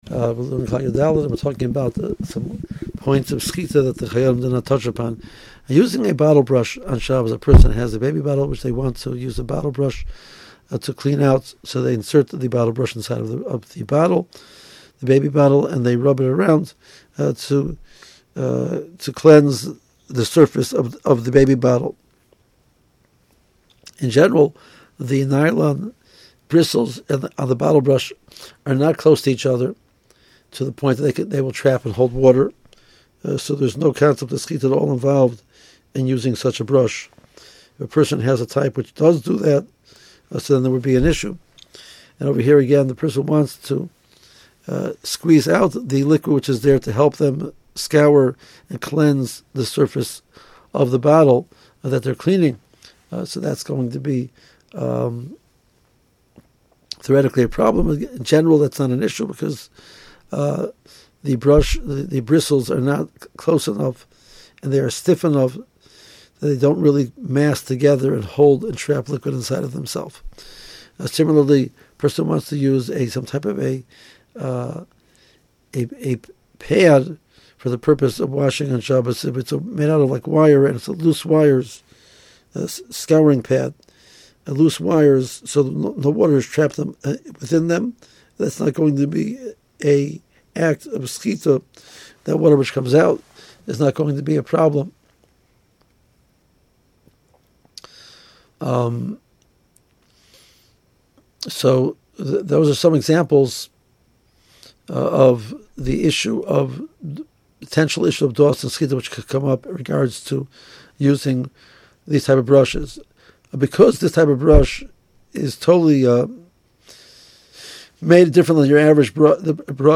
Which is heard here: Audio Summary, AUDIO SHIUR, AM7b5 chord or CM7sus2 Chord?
AUDIO SHIUR